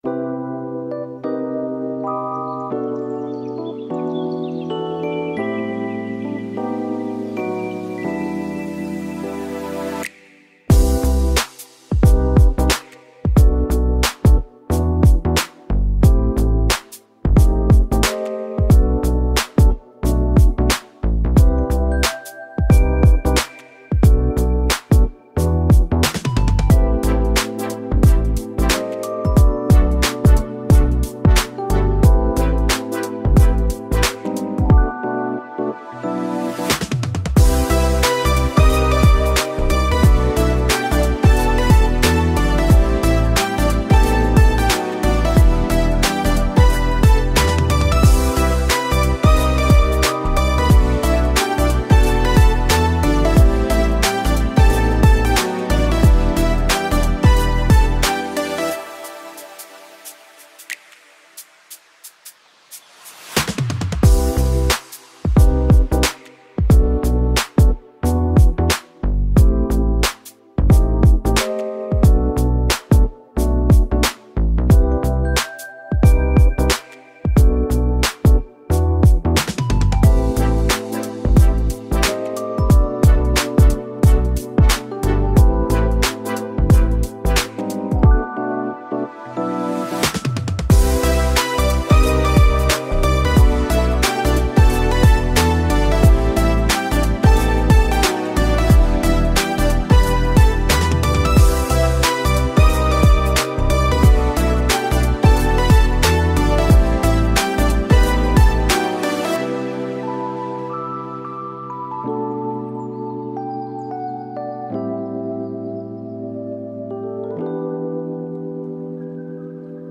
未来的で心地よいシンセポップ、きらめく虹色のサウンド、デジタルチャイム、温かみのあるベース、112bpm
明るくキュートなハイテク・ローファイ・ポップ。弾けるようなシンセサイザー、鮮やかなエレクトロニックサウンド。